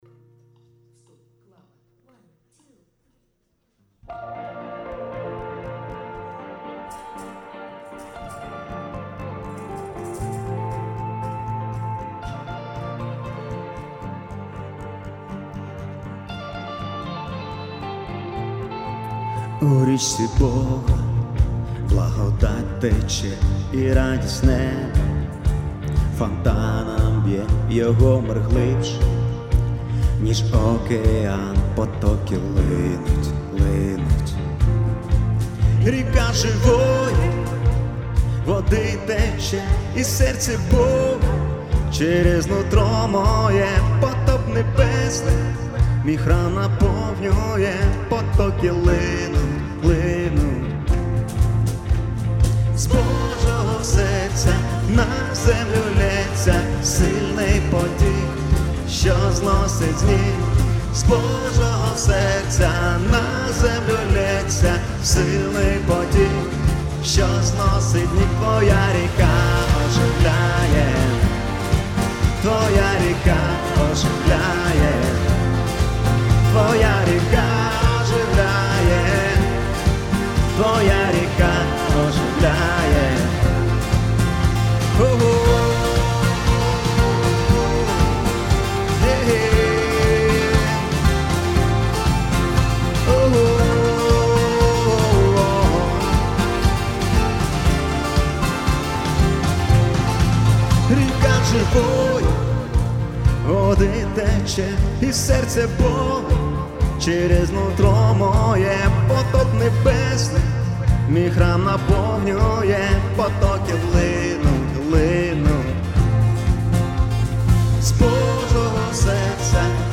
песня
118 просмотров 221 прослушиваний 4 скачивания BPM: 118